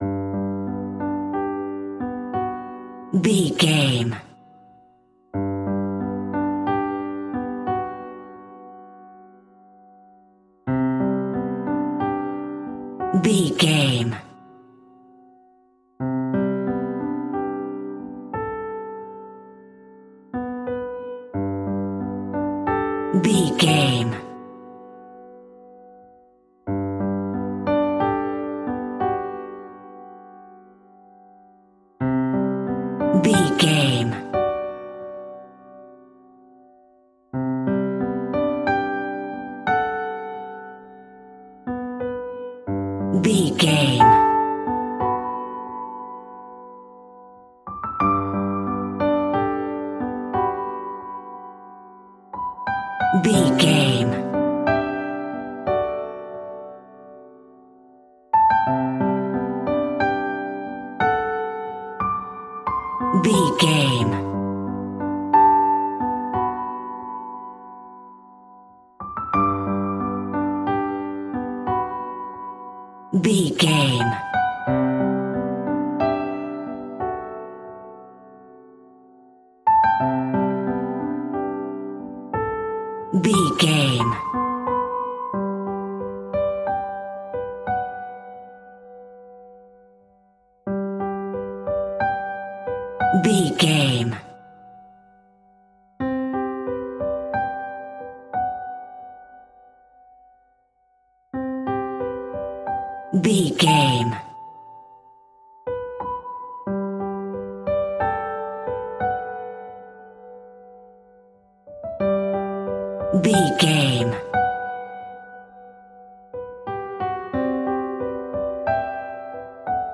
Soft airy and light classical piano music in a major key.
Regal and romantic, a classy piece of classical music.
Ionian/Major